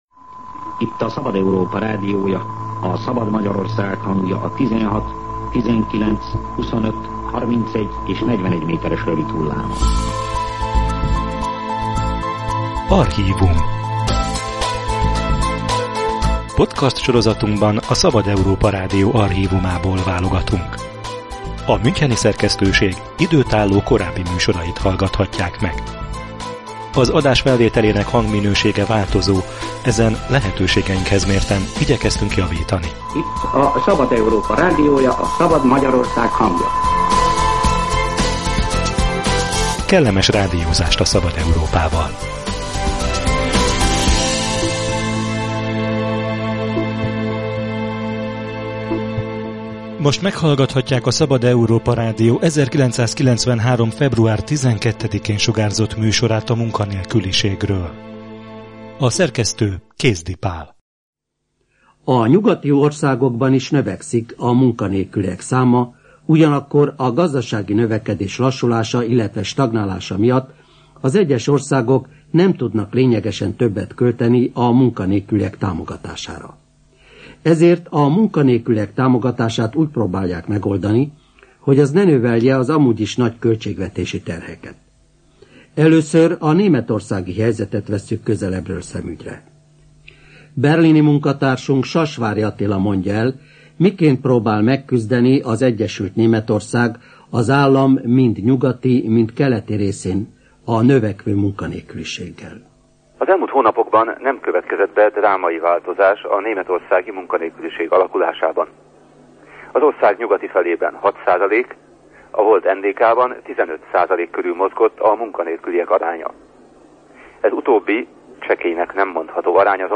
Munkahely, alanyi jogon - archív műsor a rendszerváltás idején átalakuló álláspiacról
Az állás elvesztése, a munkakeresés és a munkanélkülieknek járó támogatás ismeretlen fogalmak voltak 1990 előtt. Európai körkép a Szabad Európa Rádió 1993. február 12-ei műsorából.